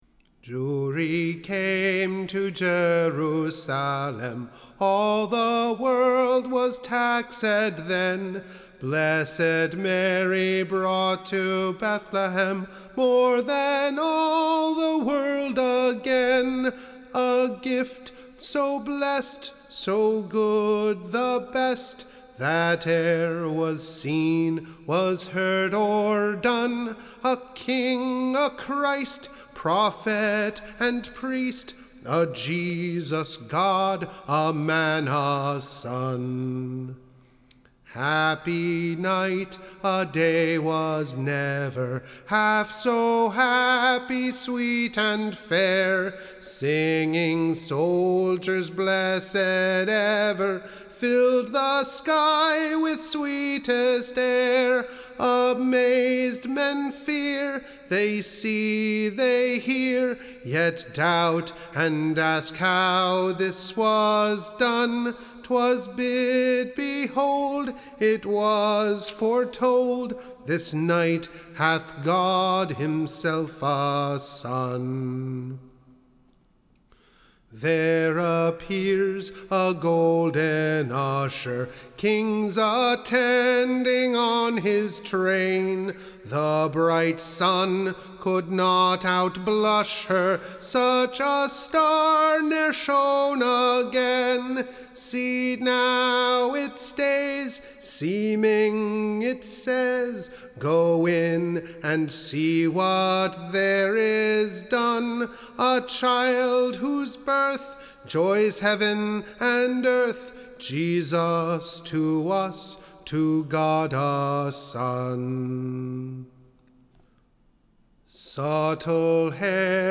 Recording Information Ballad Title An Excellent Ballad of the Birth and Passion of our / Saviour Christ. Tune Imprint Tune is, Dulcina.